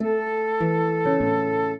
flute-harp
minuet7-4.wav